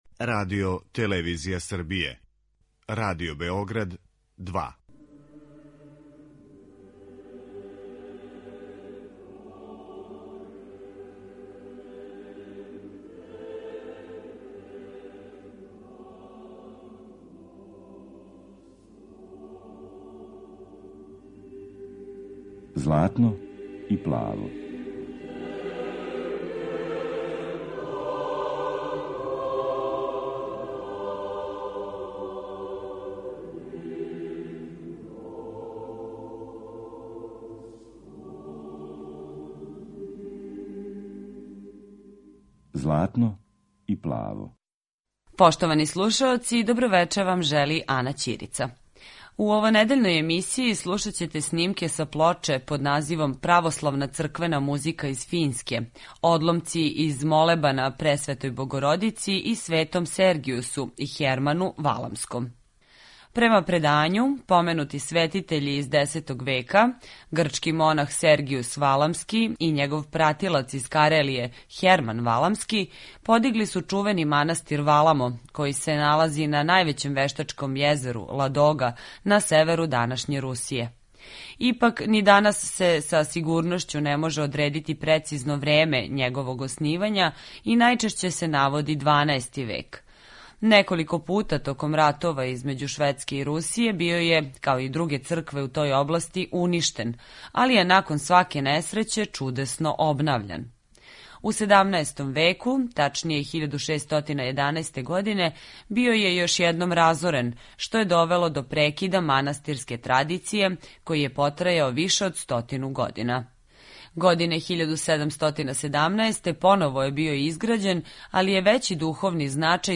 Већину старих руских напева